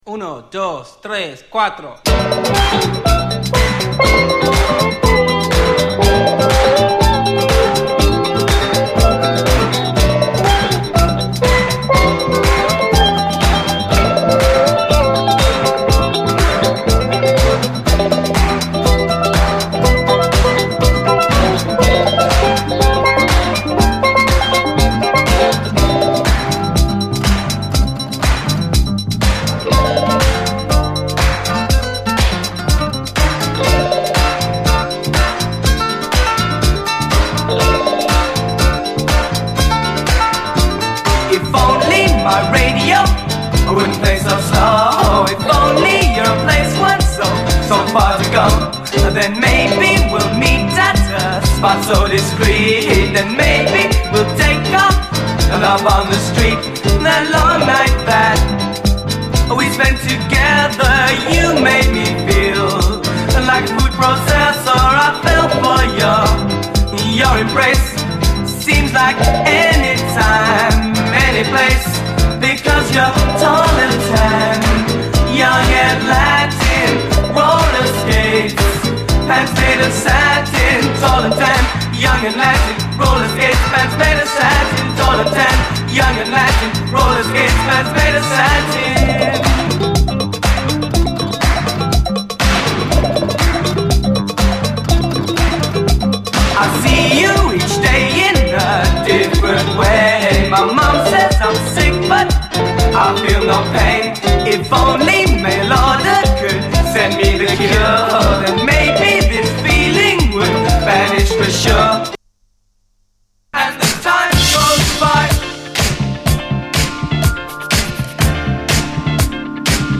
SOUL, 70's～ SOUL, DISCO, FUNK-A-LATINA
スティール・パンが弾け飛ぶ、サマー・フィール炸裂のトロピカル・ダンサー
黒人白人混成バンドで、ブリット・ファンク・シーンのバンドだったんだろうと再認識。